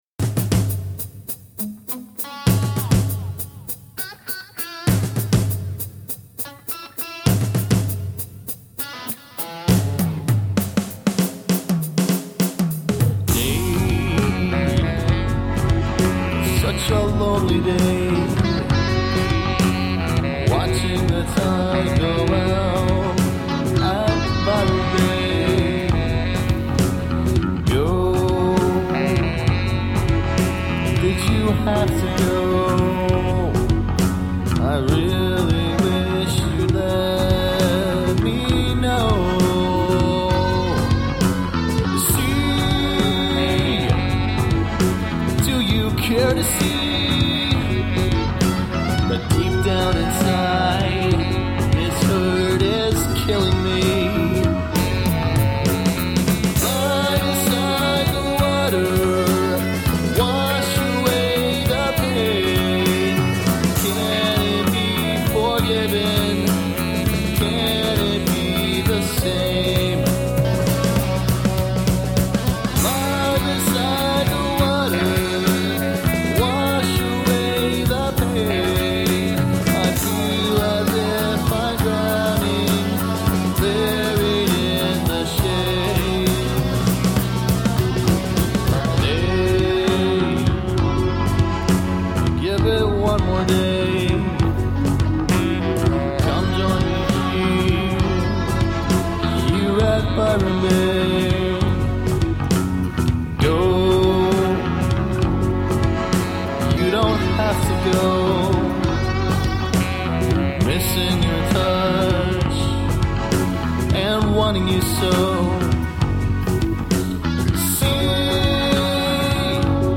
and lead guitarist